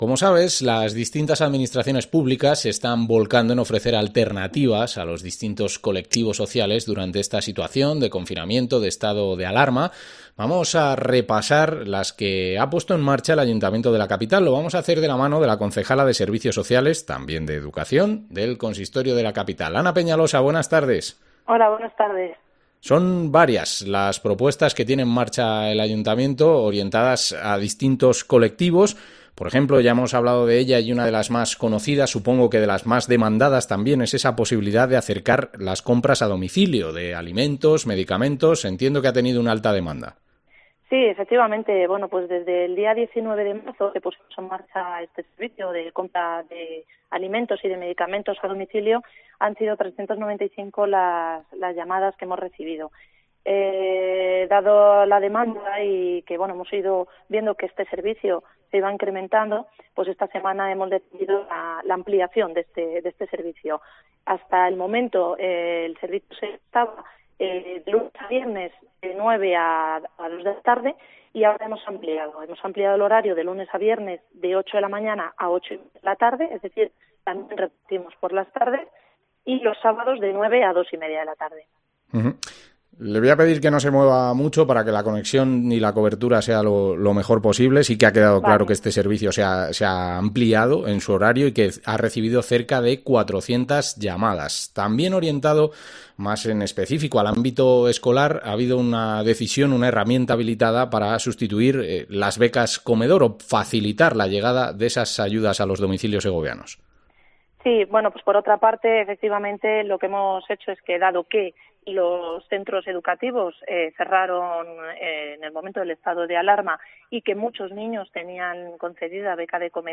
Entrevista a Ana Peñalosa, concejala de Servicios Sociales